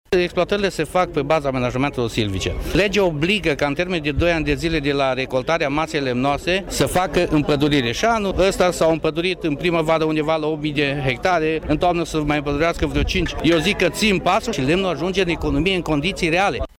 Printre oficialităţile prezente la festivitatea de deschidere s-a numărat şi Istrate Şteţco, secretar de stat în Ministerul Apelor şi Pădurilor. Oficialul guvernamental ne-a răspuns la o întrebare presantă pentru toată lumea: pot ţine campaniile de împăduriri pasul cu exploatarile forestiere masive, din ultima vreme?